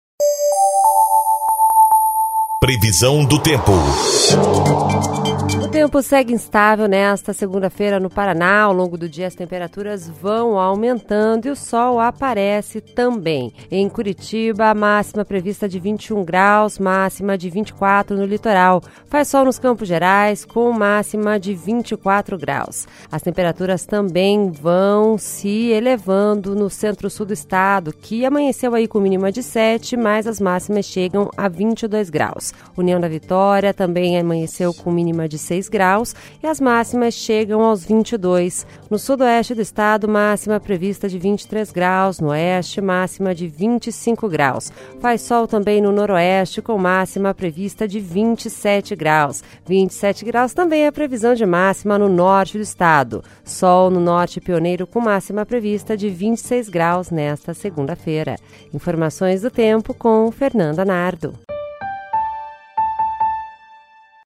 Previsão do tempo (15/05)